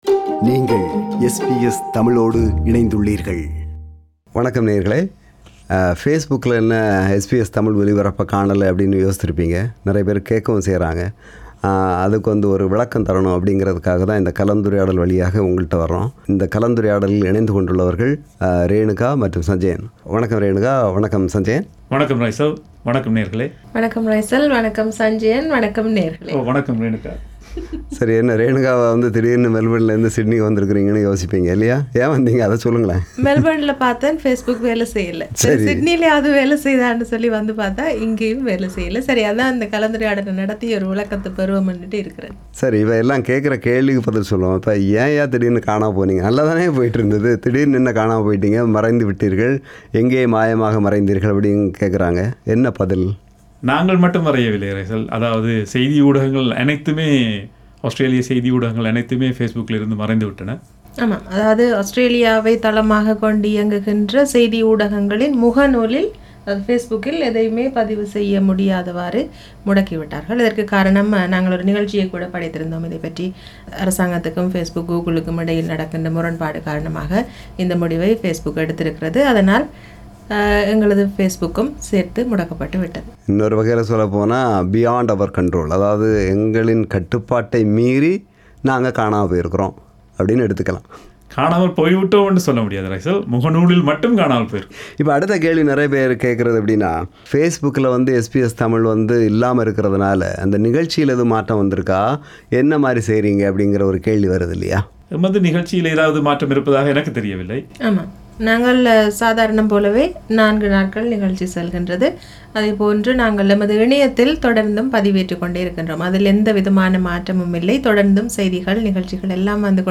நமது ஃபேஸ்புக்கில் ஆஸ்திரேலியச் செய்திகளை பகிரமுடியாதவாறு தடைவிதிக்கப்பட்டுள்ளபோதிலும் SBS தமிழ் நிகழ்ச்சிகளை வானொலி, தொலைக்காட்சி, இணையத்தளம், Podcast தளங்கள் மற்றும் வேறு பல சமூக வலைத்தளங்கள் ஊடாக நேயர்கள் தொடர்ந்தும் செவிமடுக்கலாம். இதுகுறித்த கலந்துரையாடல்.